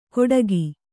♪ koḍagi